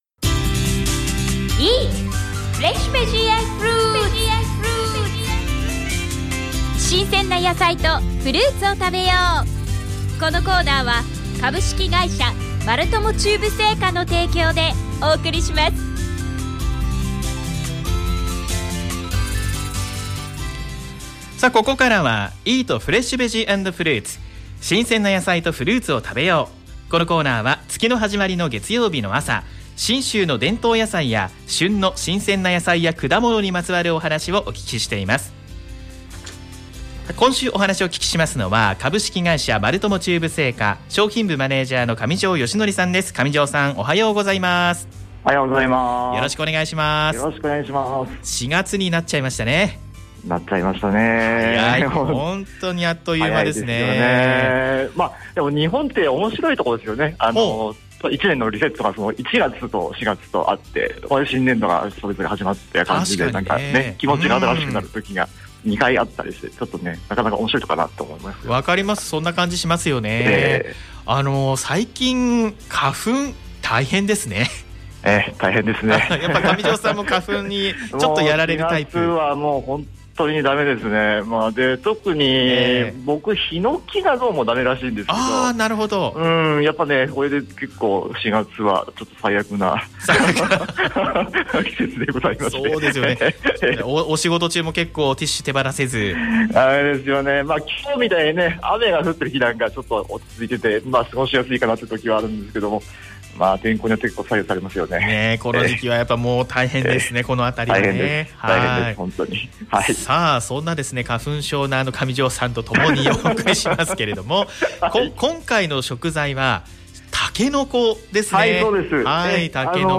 第十八回目の4月4日は「タケノコ」についてのお話。 FMまつもとさんの許可を頂きましたので、放送された音声をこちらでお聴きいただくことができます。